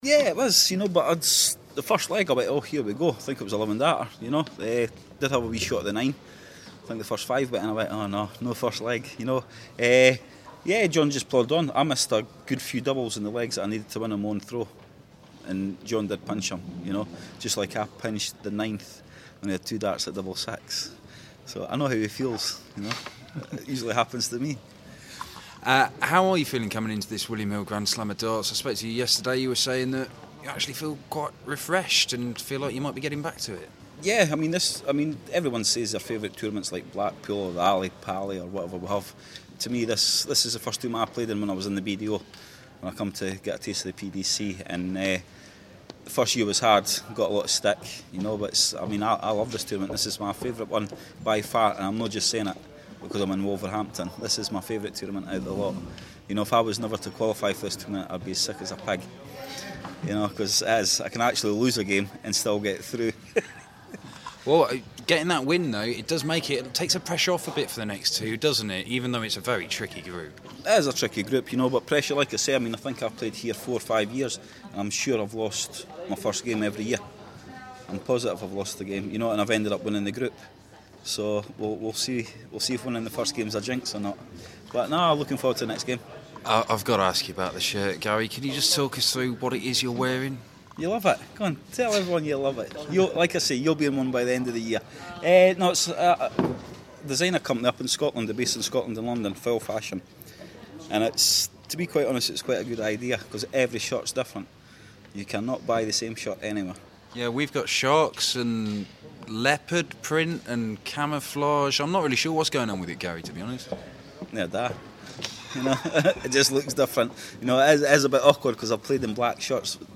William Hill GSOD - Anderson Interview